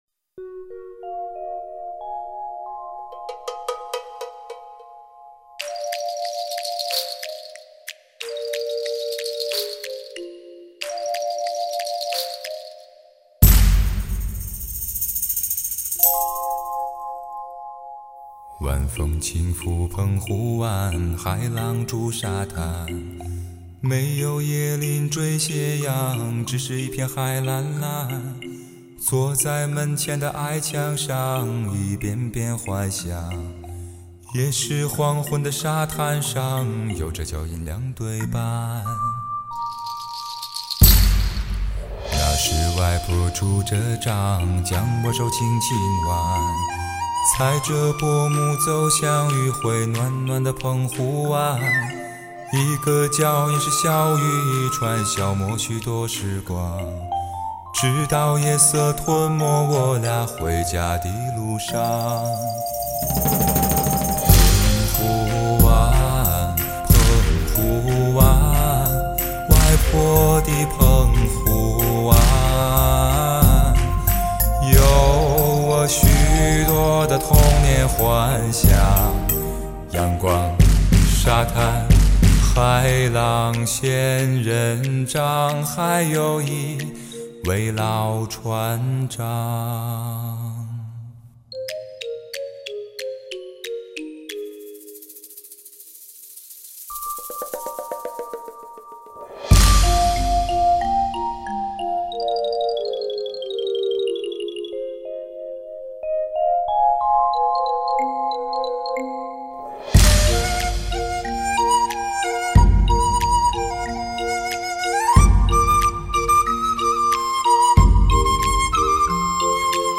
[分享]来听~人声低音炮！----之三